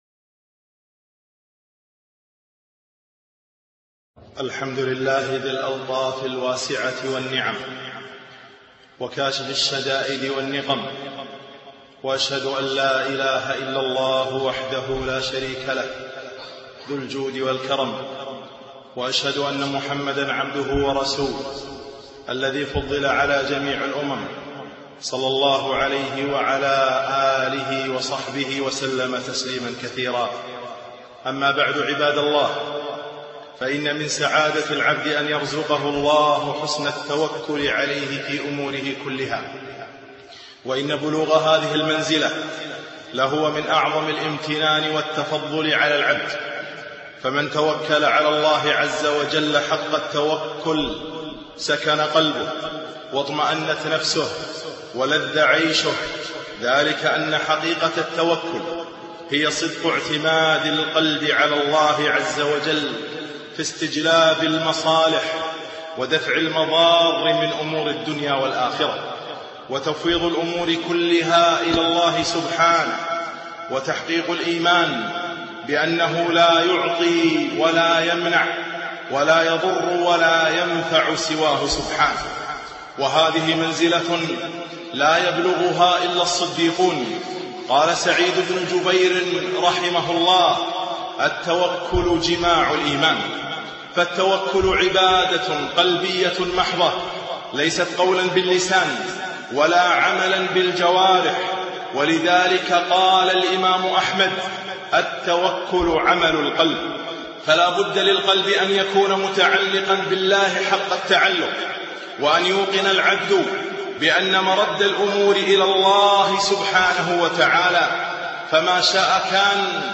خطبة - التوكل علي الله